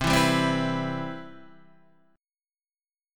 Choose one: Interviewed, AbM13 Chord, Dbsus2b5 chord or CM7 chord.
CM7 chord